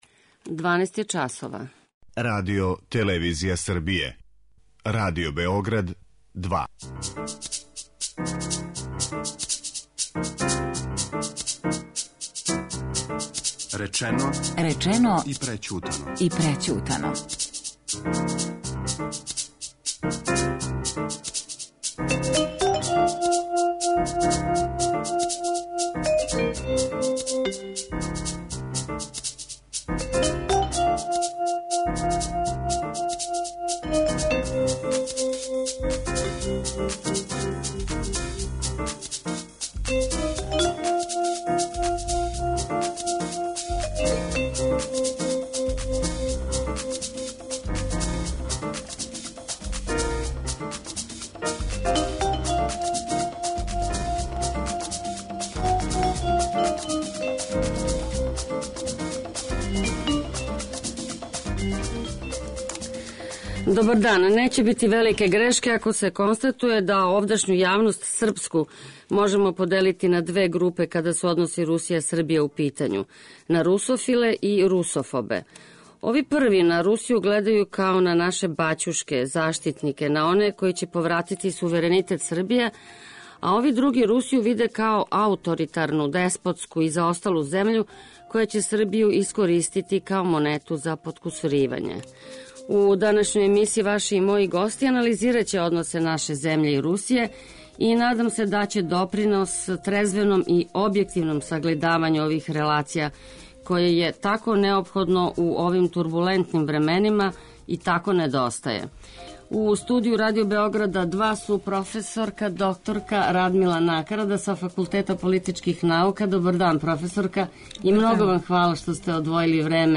О односима две земље говоре